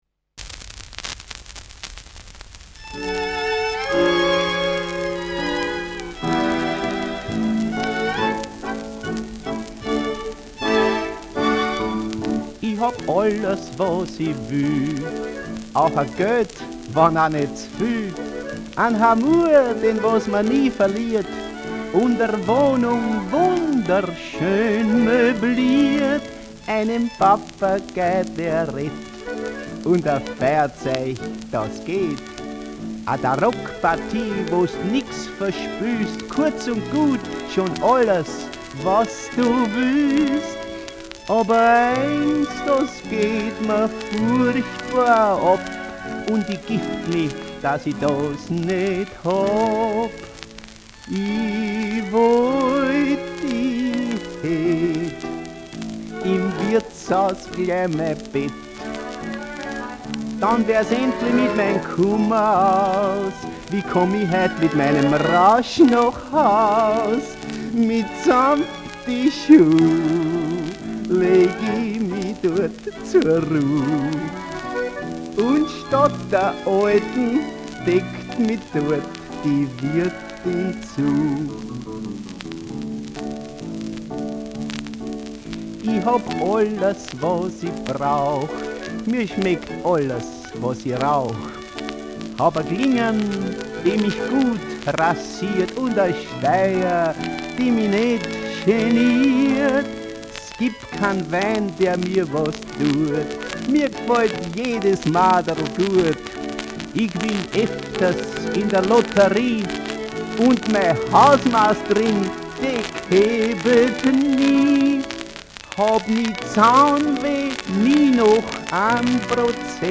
Schellackplatte
[unbekanntes Ensemble] (Interpretation)